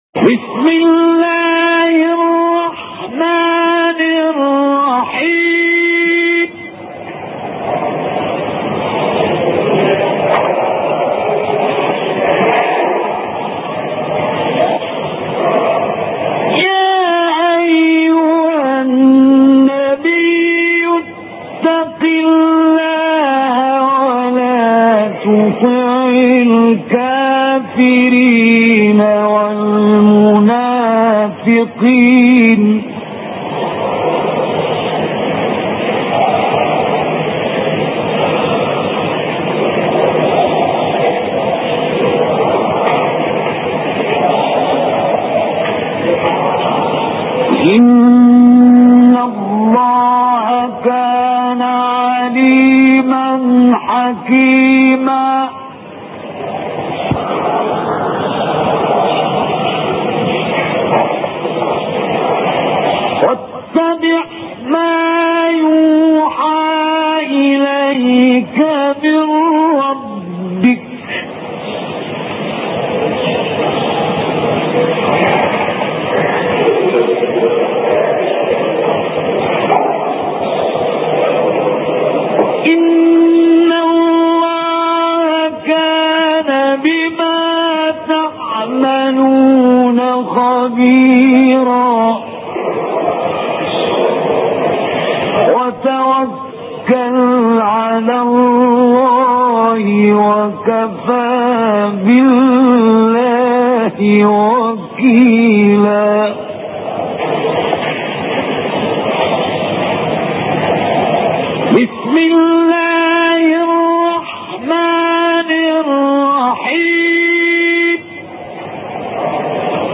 تلاوت آیاتی از سوره احزاب